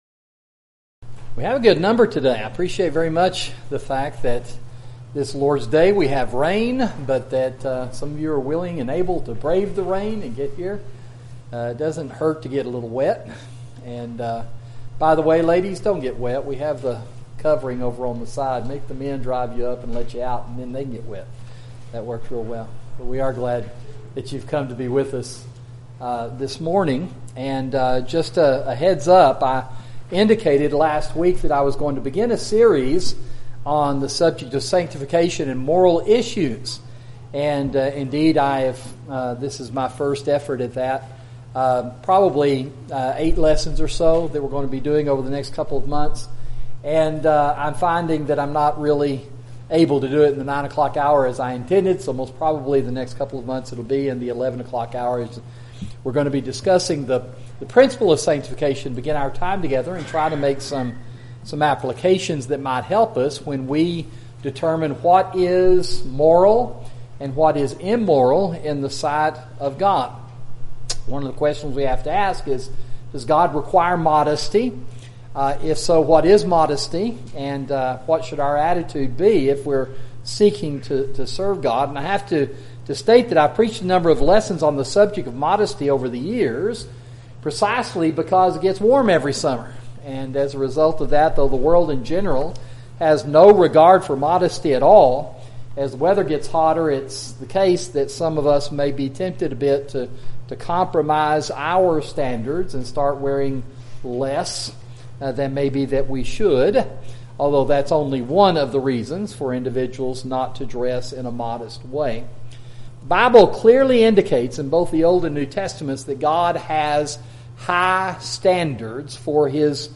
Sermon: Sanctification and Modesty